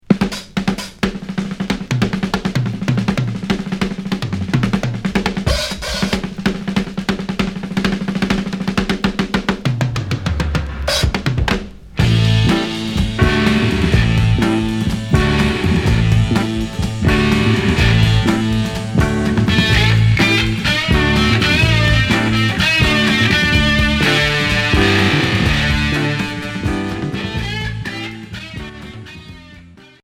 Heavy rock pop Troisième 45t retour à l'accueil